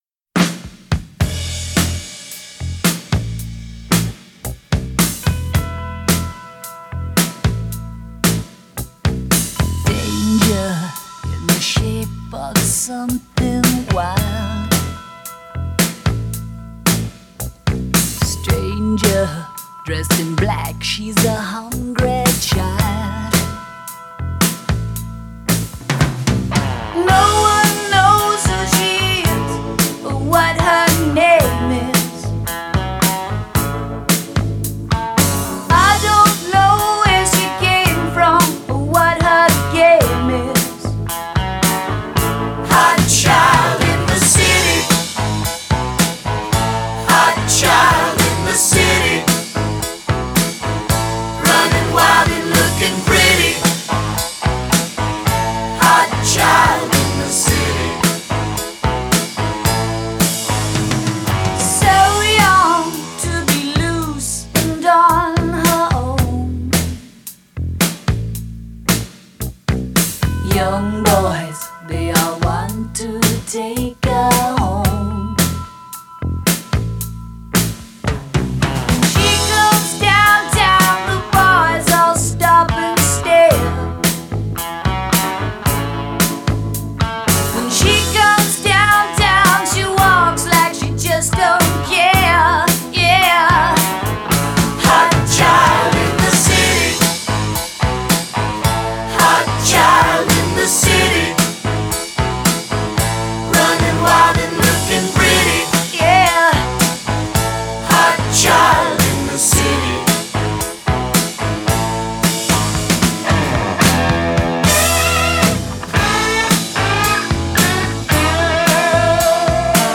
Great guitar solo in that one.